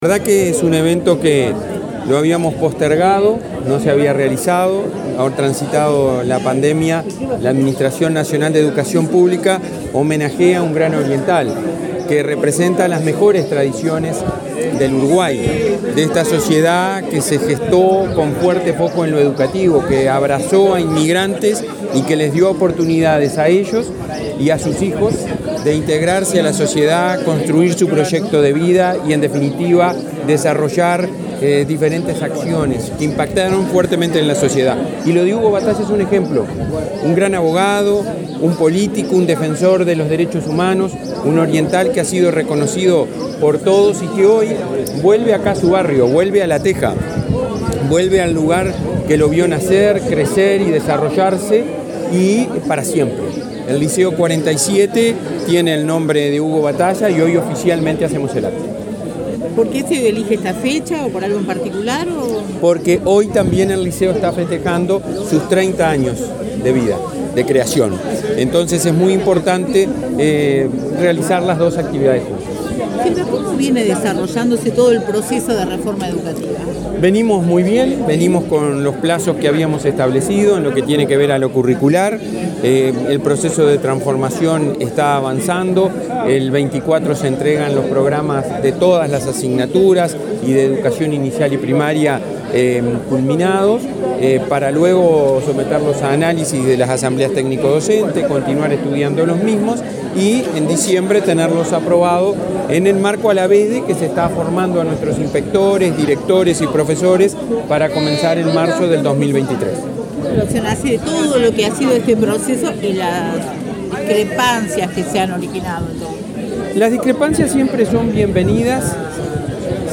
Declaraciones del presidente de la ANEP a la prensa
Declaraciones del presidente de la ANEP a la prensa 21/10/2022 Compartir Facebook X Copiar enlace WhatsApp LinkedIn La ANEP realizó este viernes 21 un acto oficial por la denominación del liceo n.º 47, de La Teja, en Montevideo, como "Dr. Hugo Batalla". El presidente de la ANEP, Robert Silva, dialogó con la prensa acerca de la figura del extinto político y temas educativos.